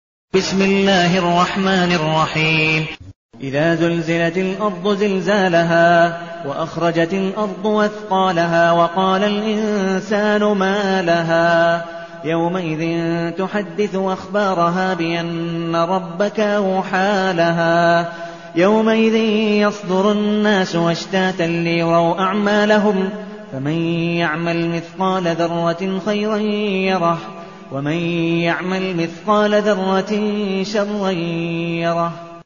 المكان: المسجد النبوي الشيخ: عبدالودود بن مقبول حنيف عبدالودود بن مقبول حنيف الزلزلة